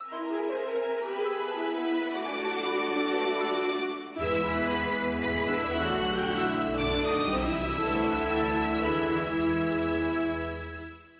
Ripped from the game
applied fade-out
Fair use music sample